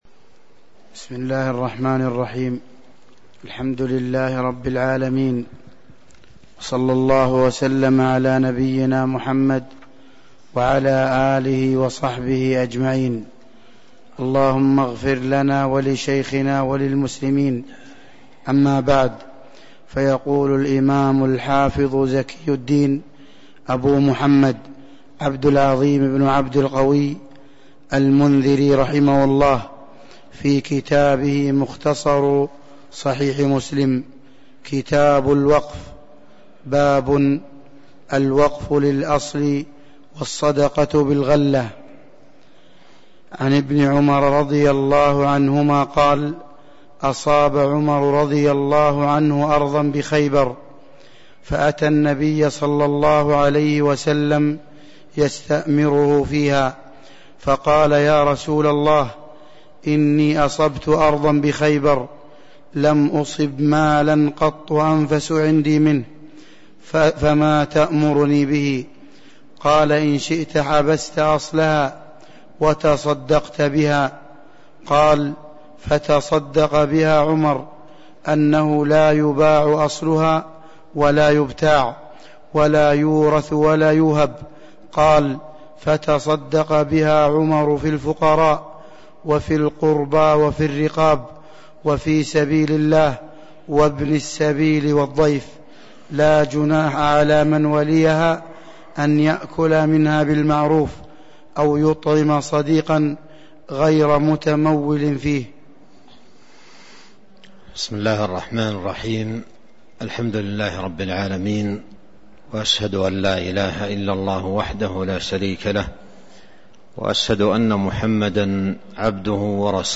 تاريخ النشر ٢٣ صفر ١٤٤٣ هـ المكان: المسجد النبوي الشيخ